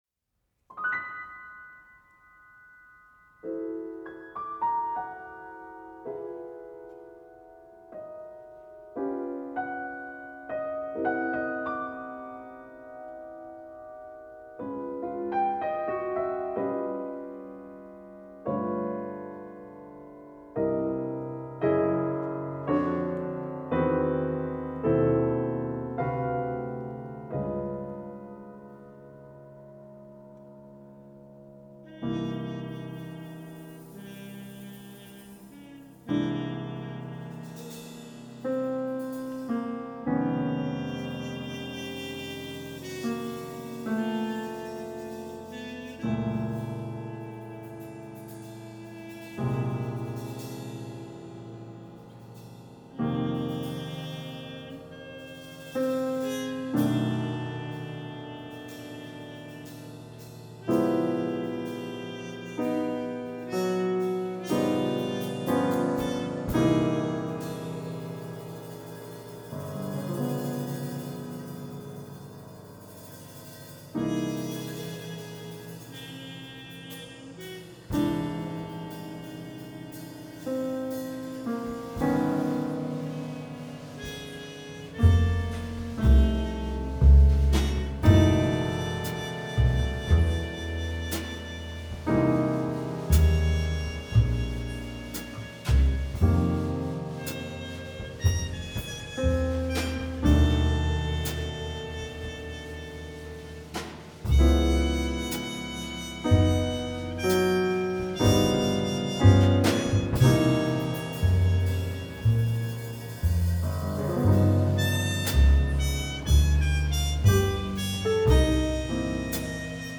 Late-Night Music, for Trumpet, Piano, String Bass, and Drum Kit
Composed in the spring of 1990, Late Night Music is a jazz-influenced work based on a repeating 12-bar blues pattern. The harmony is essentially tonal, although I was particularly interested in combining the kind of chord substitutions one might find in jazz music with the free sonorities I tend to use in atonal music.
Eighth notes are to be played “straight,” and not swung; when I want a swing feeling I write triplets.